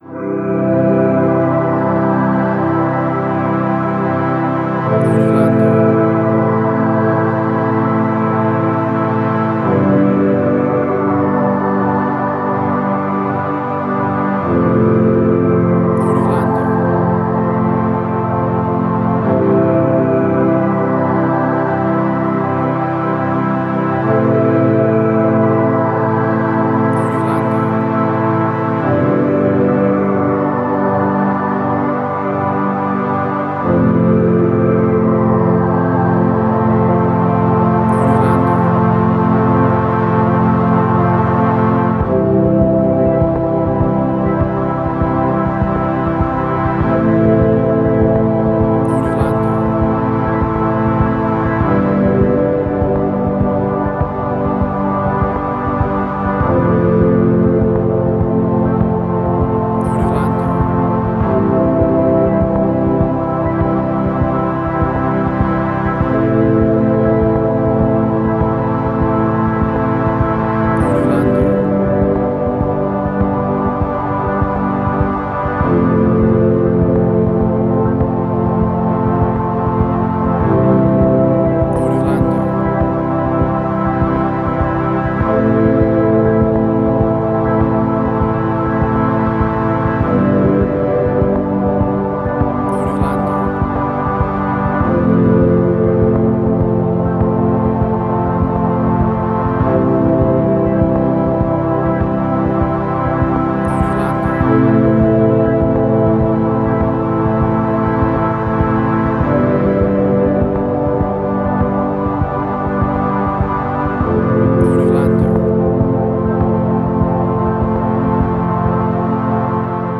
Atmospheric and ambient sounds from the night!
Tempo (BPM): 100